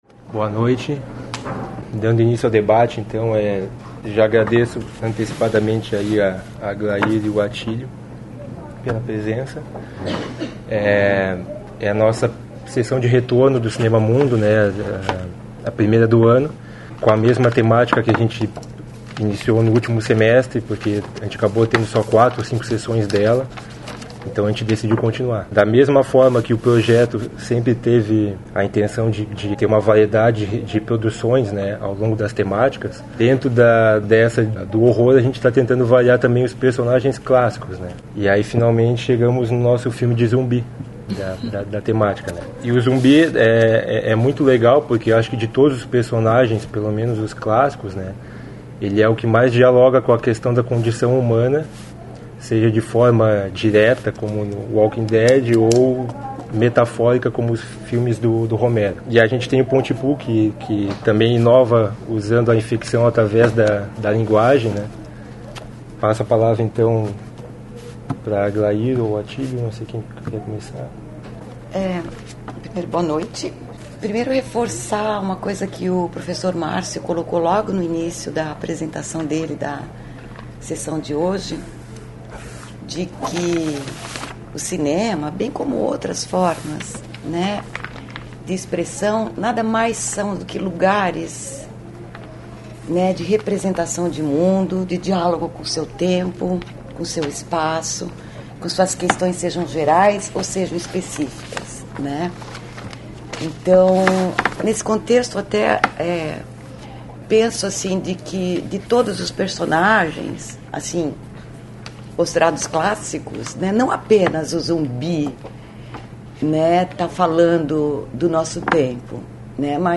Comentários do filme "Pontypool"
Comentários dos debatedores convidados